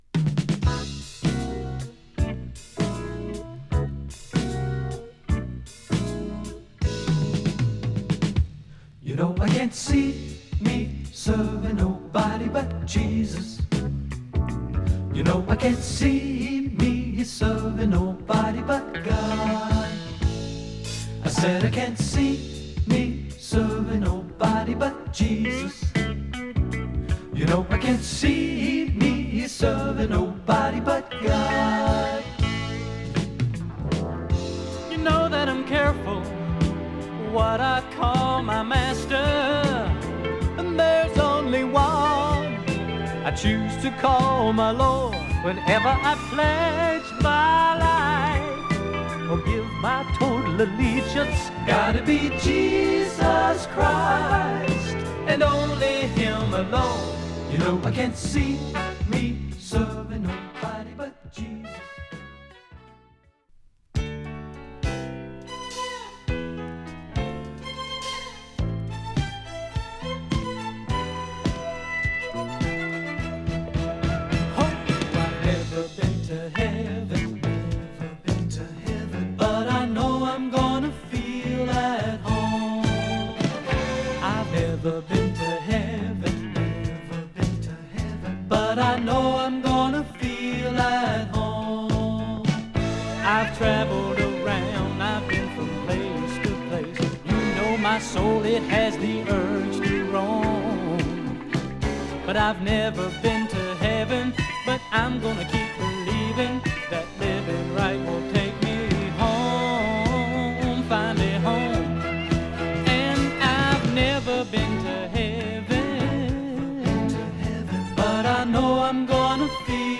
ナッシュビルからレアなレリジャスAOR作品。